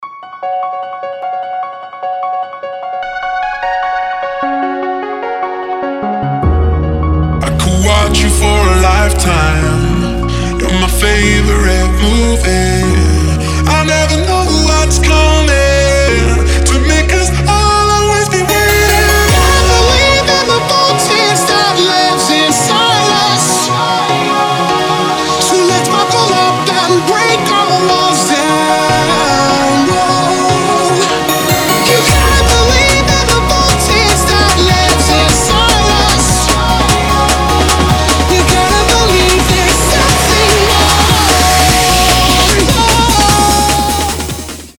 • Качество: 320, Stereo
Electronic
vocal